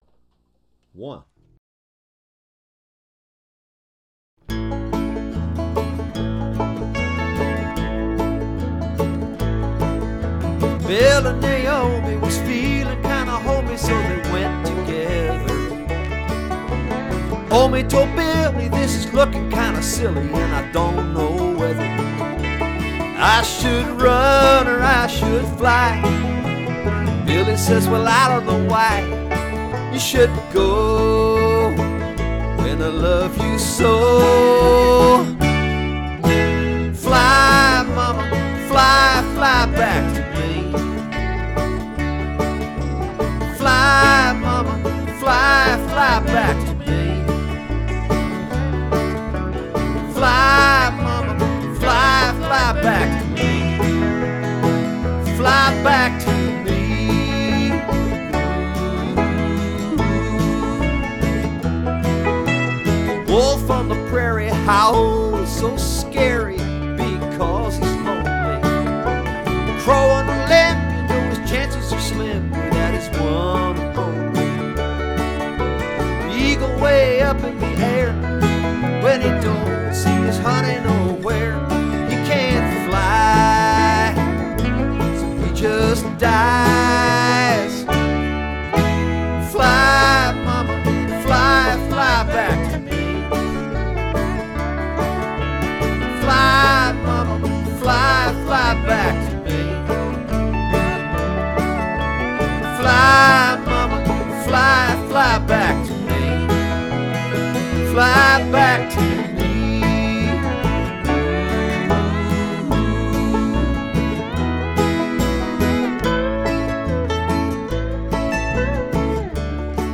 Warning!! It’s very catchy:)